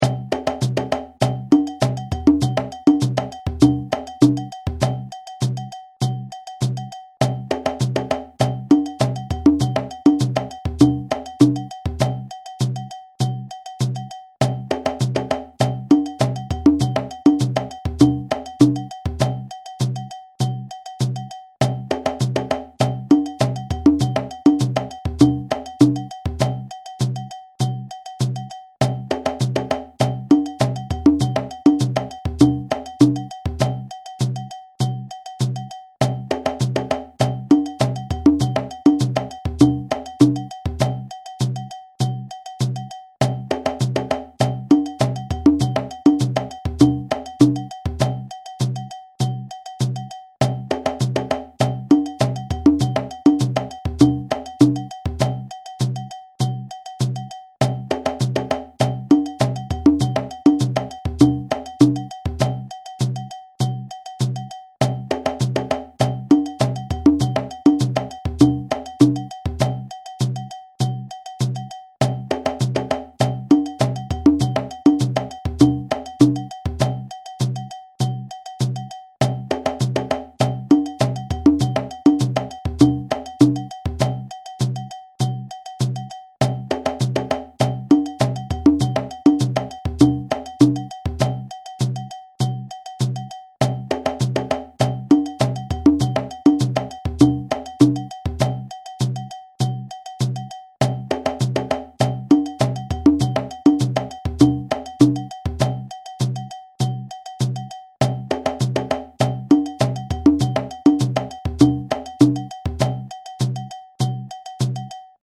audio (with shekeré & bell)